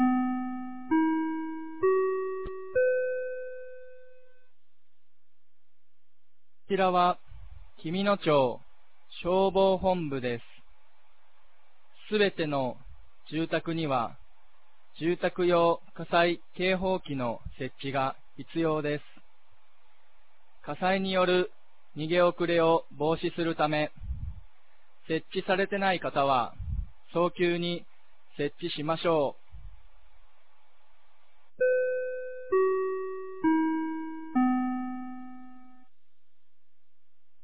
2025年12月06日 16時00分に、紀美野町より全地区へ放送がありました。